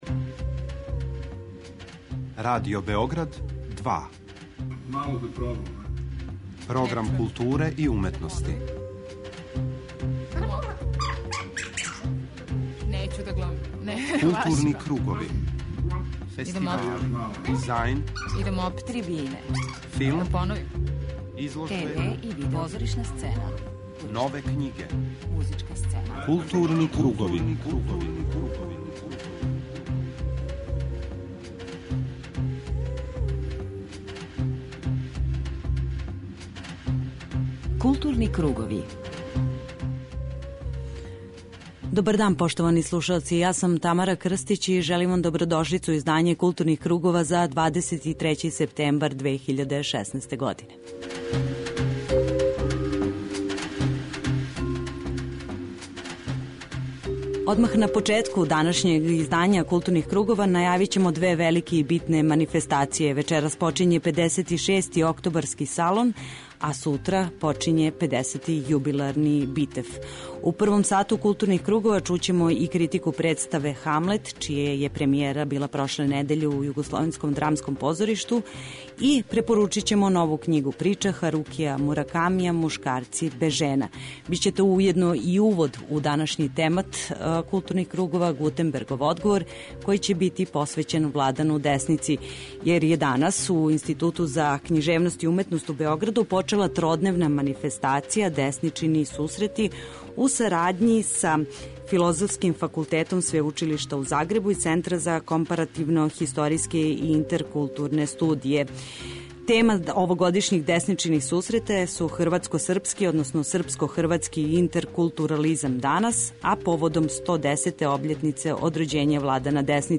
Слушаћемо разговор са учесницима овог научног скупа о рецепцији стваралаштва Владана Деснице, али и онога што се назива заједничким наслеђем